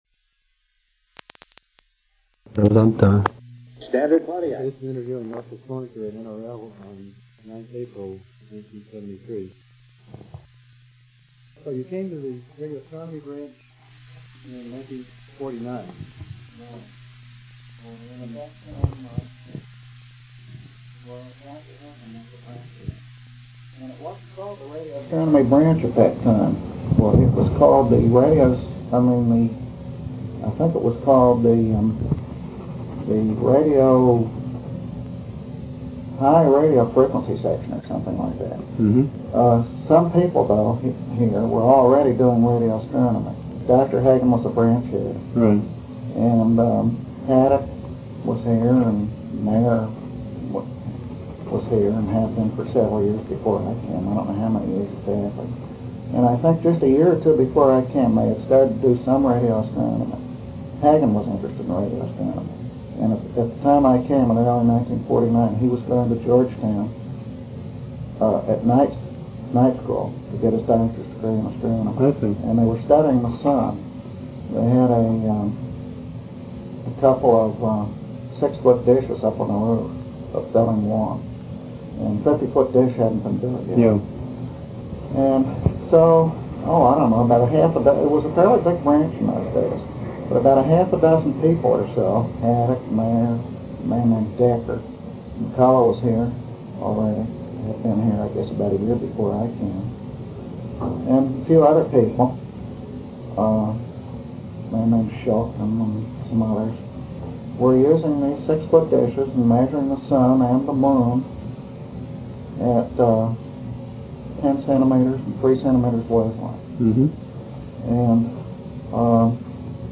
Type Oral History
Original Format of Digital Item Audio cassette tape
Interview Topics 1949-55 solar eclipse expeditions at Naval Research Lab, 10-cm radio sources on 50 ft, planets on 50 ft, radio sources on 84 ft, polarization on 300 ft. Notes Audio poor.